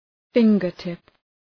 Shkrimi fonetik {‘fıŋgər,tıp}